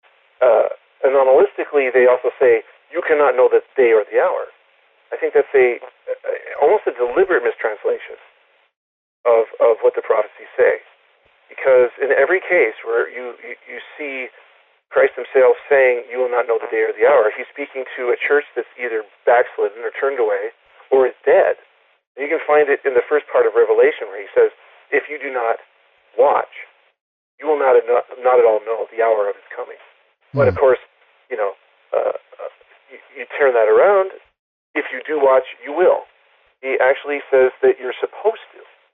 Legacy Links: Red Ice Interviews